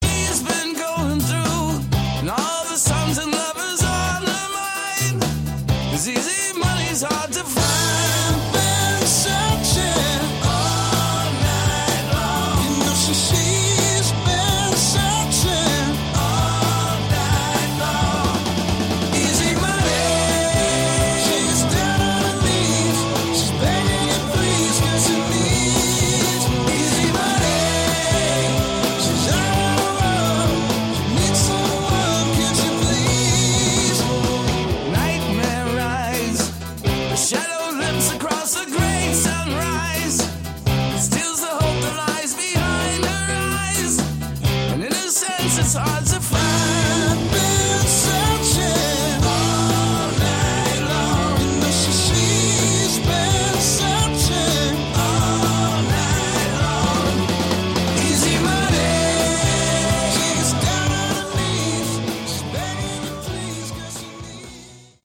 Category: Classic Hard Rock